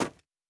SnowSteps_05.wav